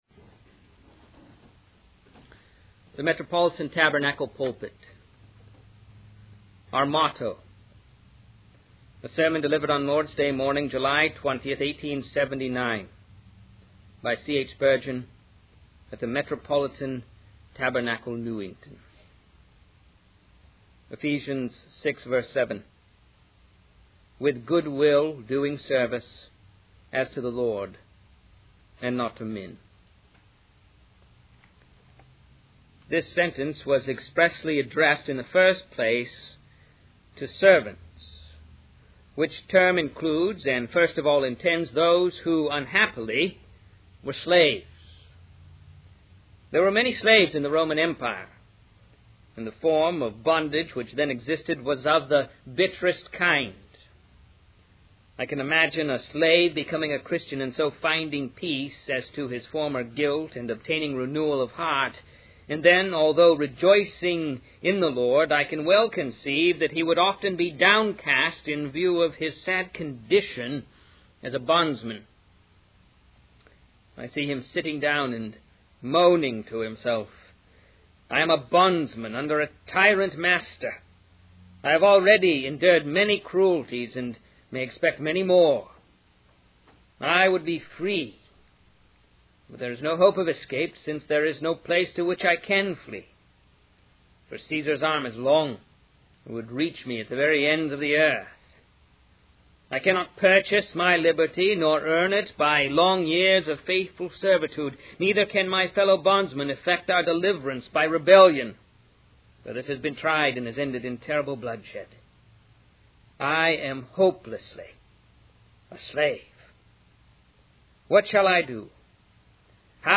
In this sermon, the preacher describes a scenario of a bondsman under a tyrant master who is enduring cruelties and feels hopeless in his situation.